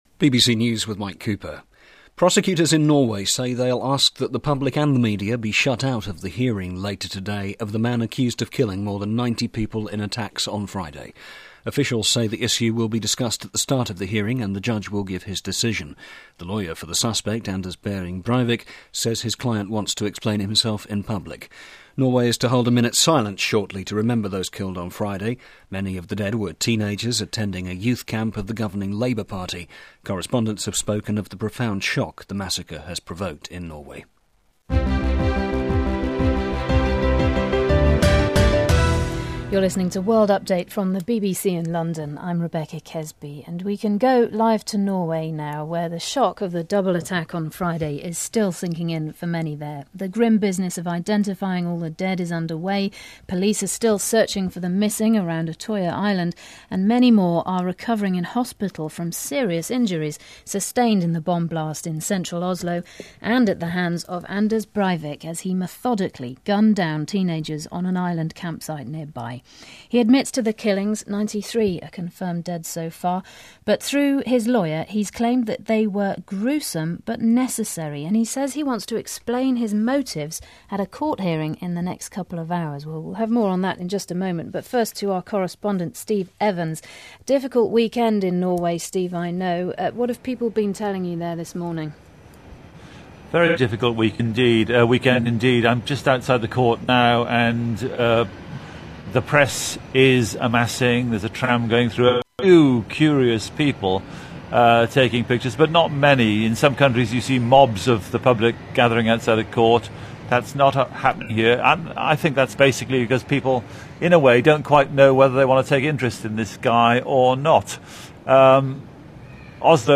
July 25, 2011 – BBC World News – Report on Oslo terror attack aftermath – BBC World Service